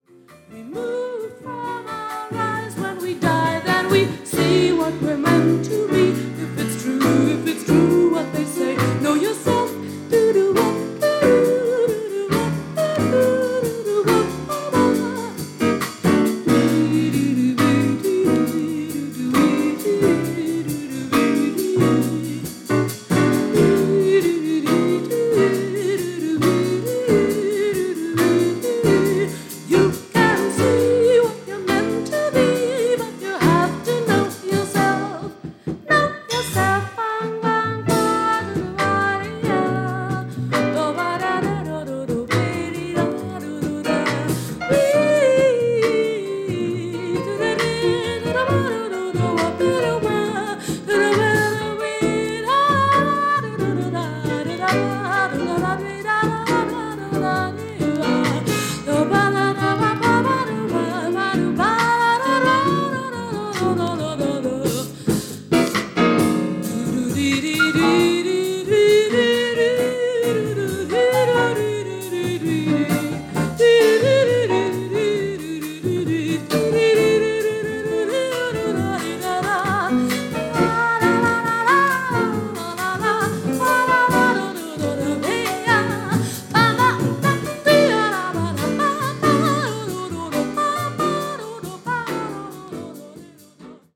Jazz Vocal